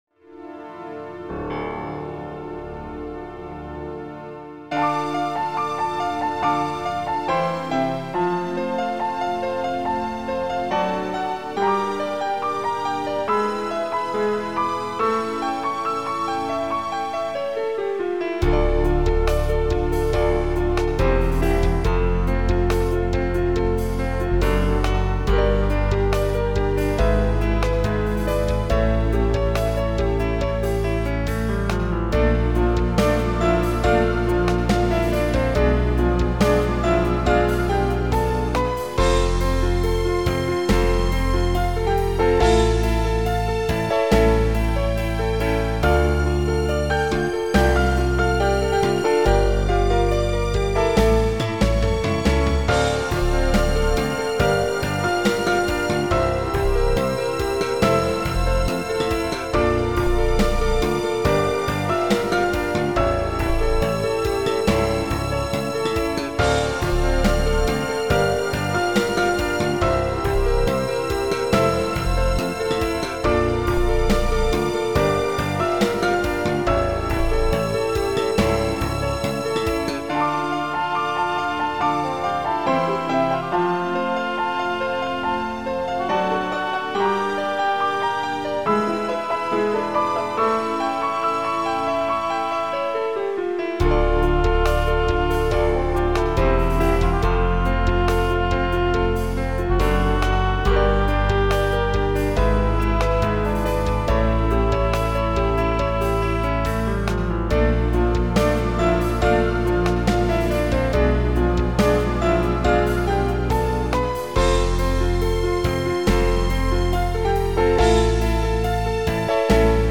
xm (FastTracker 2 v1.04)
Piano Synth
Pad
Fretless Bass
Snare
Marcato Strings
JazzRideCymbal
Funk Bass
Oboe
Pizzizato Strings